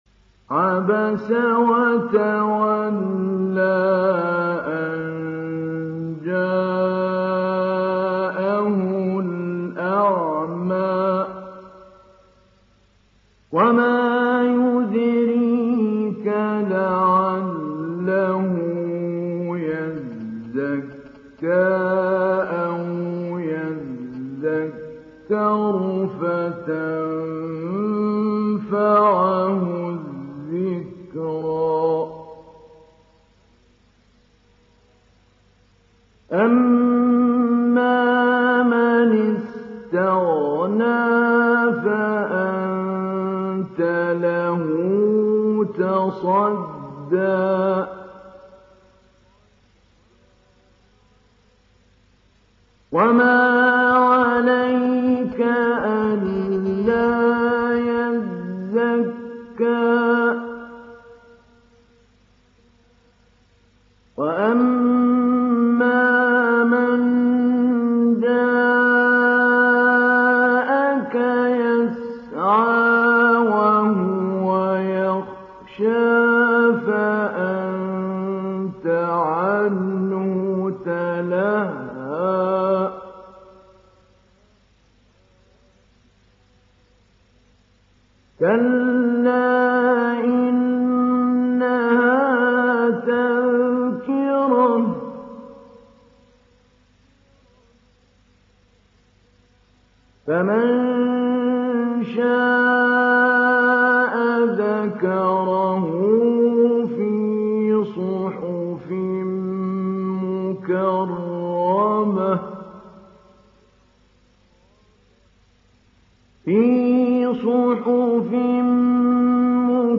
تحميل سورة عبس محمود علي البنا مجود